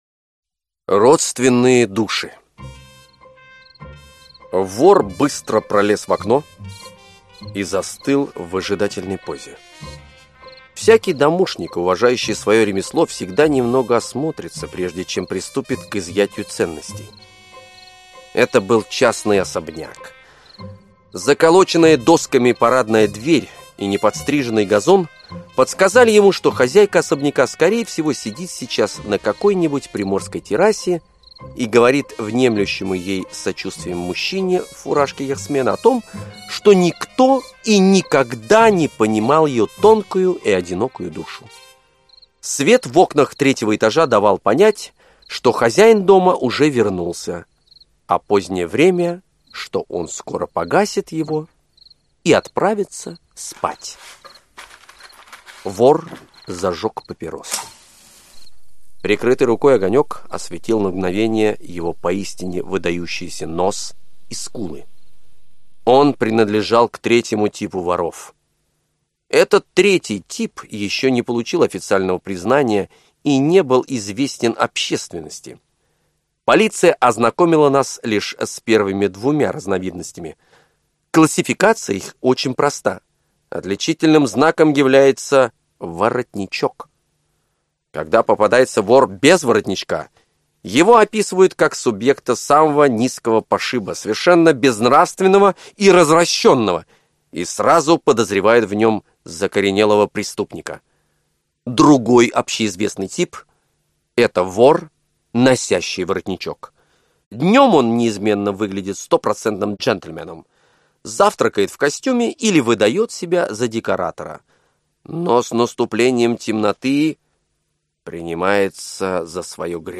Аудиокнига Родственные души. 10 рассказов в аудиоспектаклях | Библиотека аудиокниг
Aудиокнига Родственные души. 10 рассказов в аудиоспектаклях Автор О. Генри Читает аудиокнигу Лев Дуров.